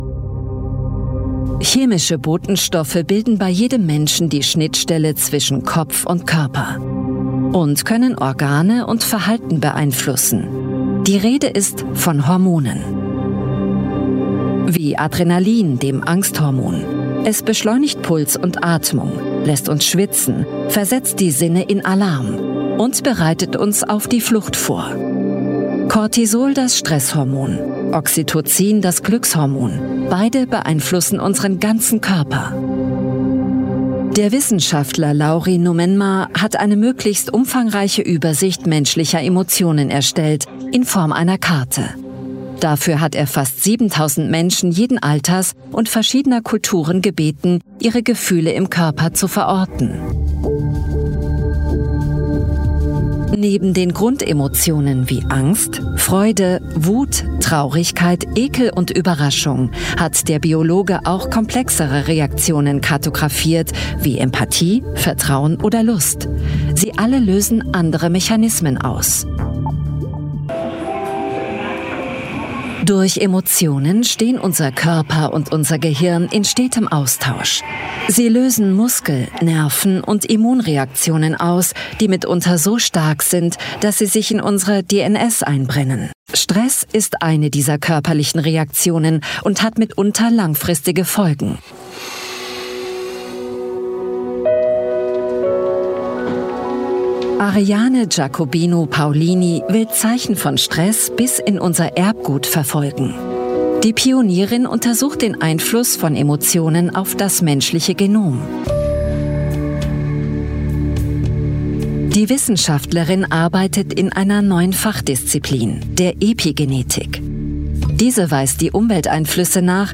Native voices